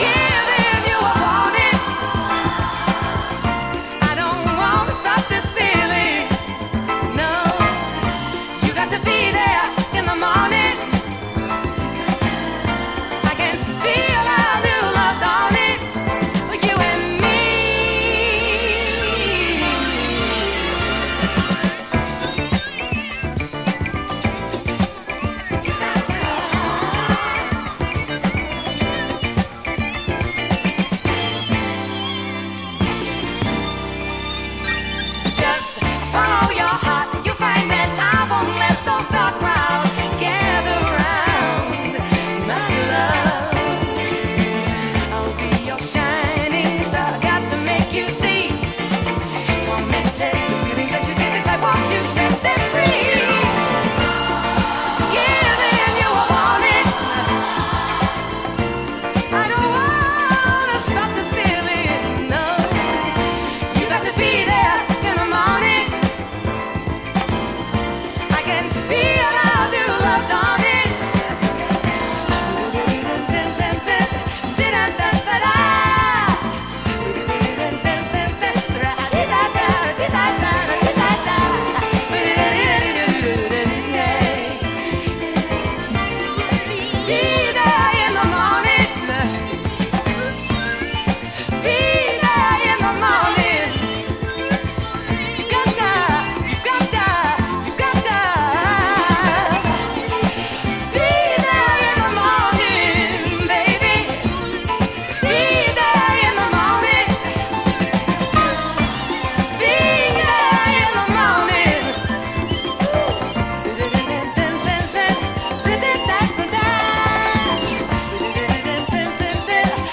Australian blue-eyed soul singer